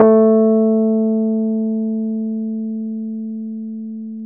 RHODES-A2.wav